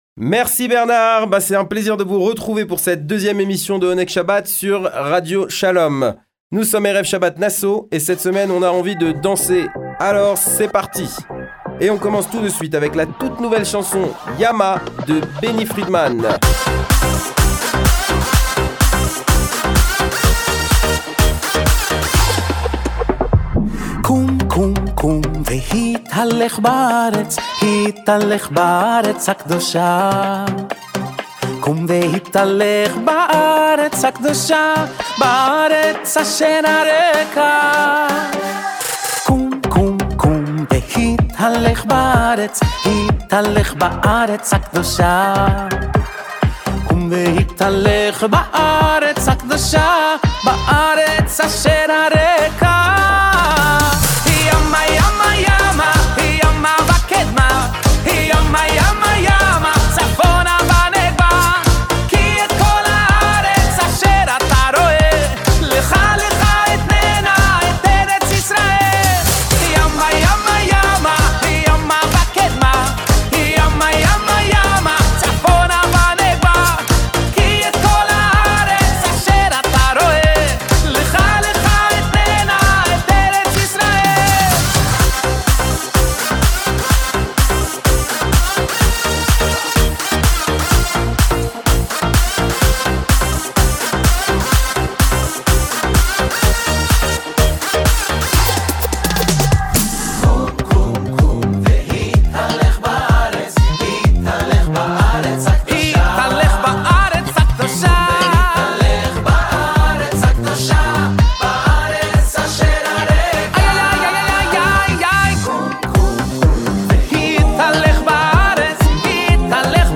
Le meilleur de la musique juive, tous les vendredis après-midi juste après Kabalat shabat, aux alentours de 17h20 !